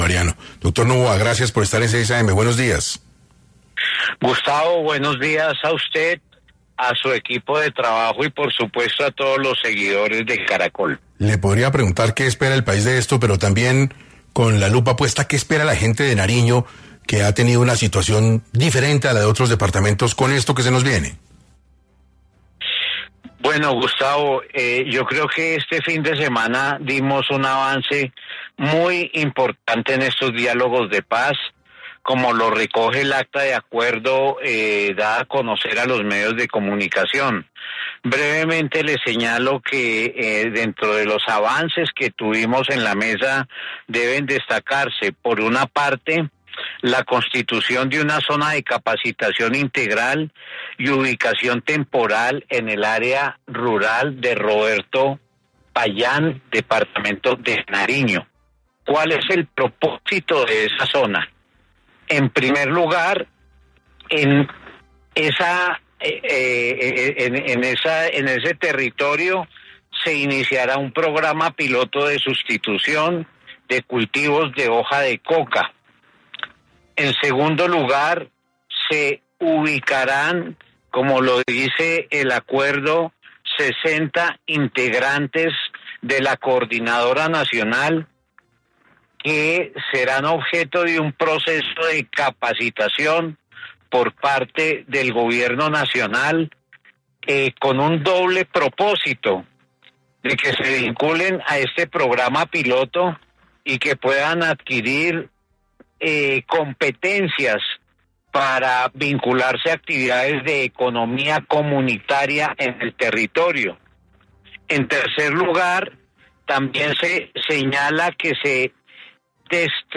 En entrevista con 6AM de Caracol Radio, Novoa detalló los principales avances con el Ejército Bolivariano, específicamente en Nariño, que incluyen: un programa piloto de sustitución de cultivos de coca, la transición a economías comunitarias y el inicio de destrucción de armamento.